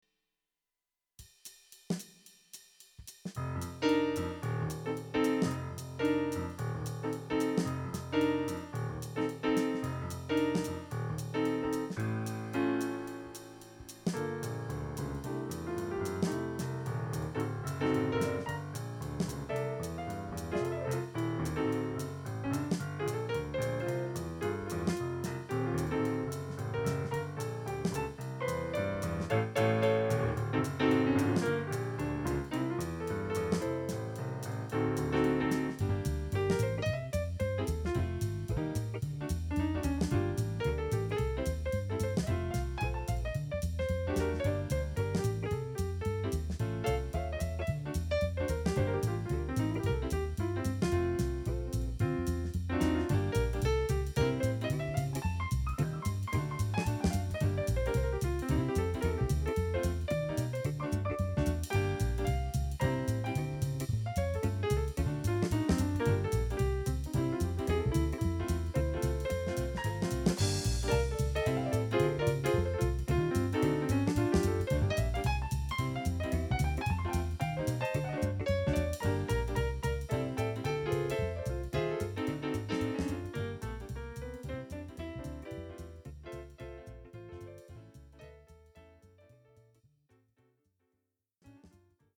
I decided to hit record on my H4N while playing.